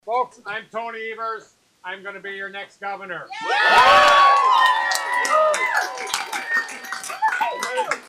During a rally of his supporters, Evers explained that UW-Manitowoc is on the ballot in next Tuesday’s Governor’s race.